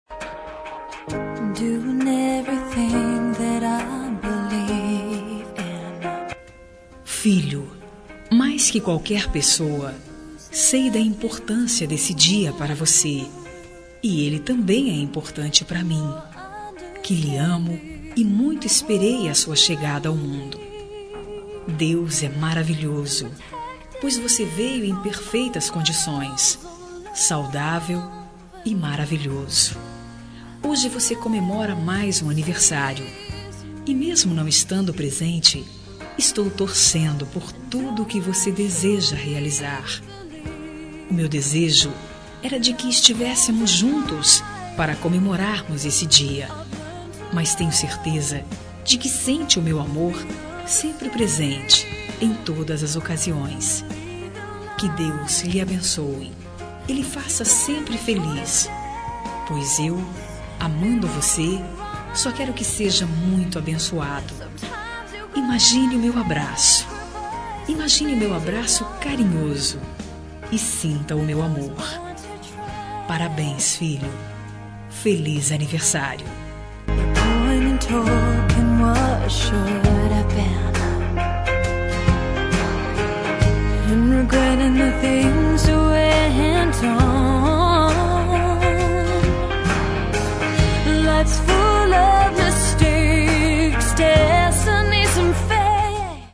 Aniversário de Filho – Voz Feminina – Cód: 5301 – Distante
5301-aniver-filho-distante-fem.mp3